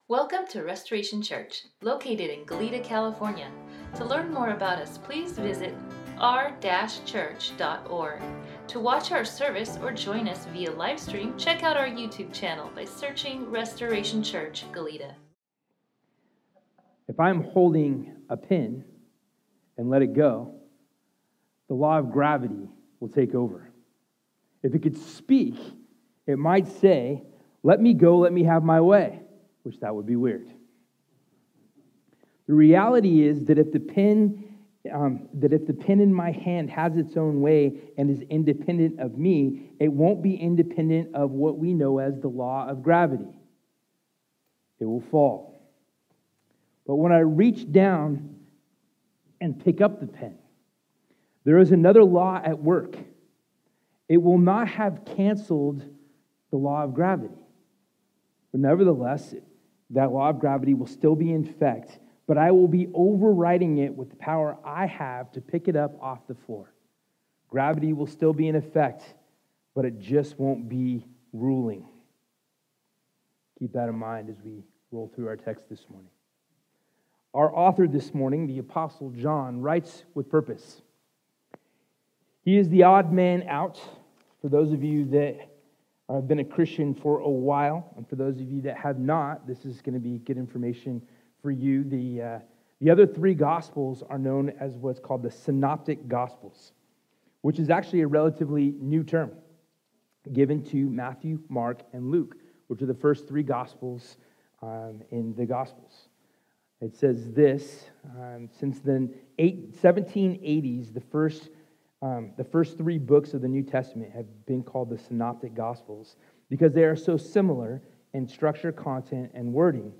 Crucified Sermon NotesDownload If you are checking out our church and have a question, need a bible, want to visit one of our community groups, and/or need prayer FOR ANYTHING, send us an EMAI…